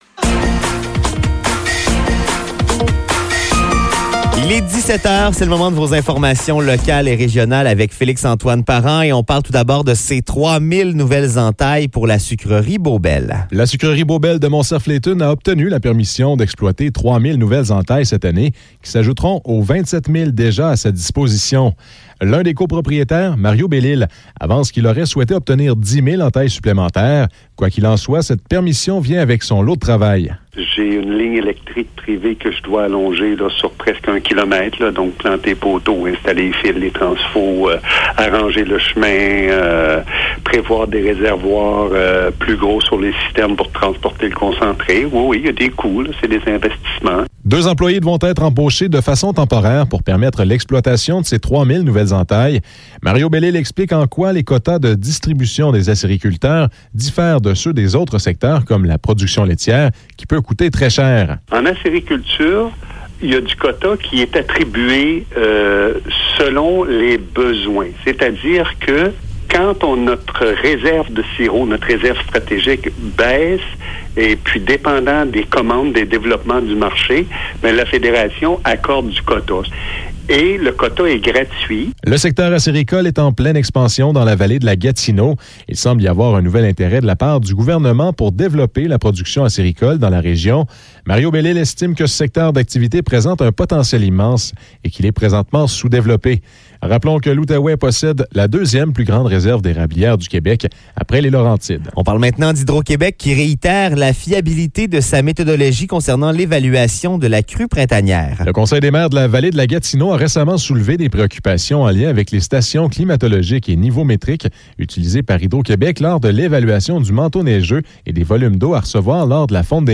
Nouvelles locales - 27 avril 2023 - 17 h